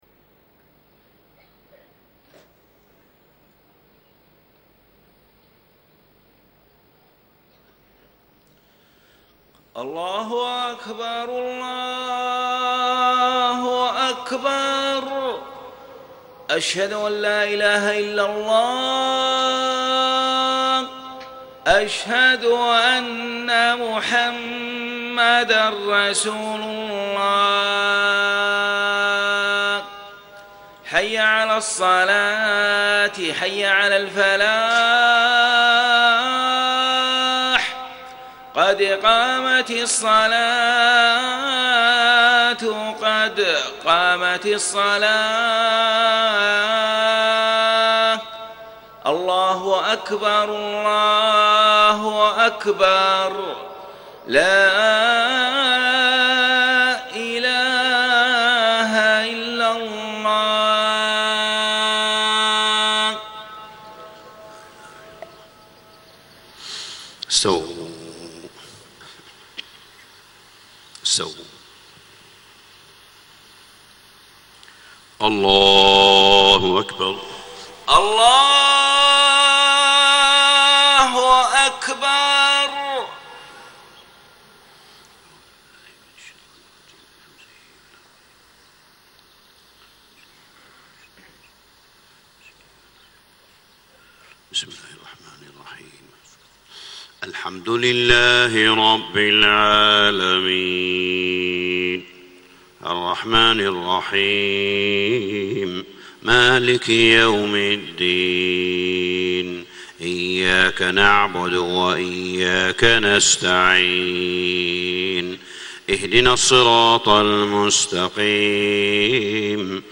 صلاة الفجر 5-7-1434هـ من سورة إبراهيم > 1434 🕋 > الفروض - تلاوات الحرمين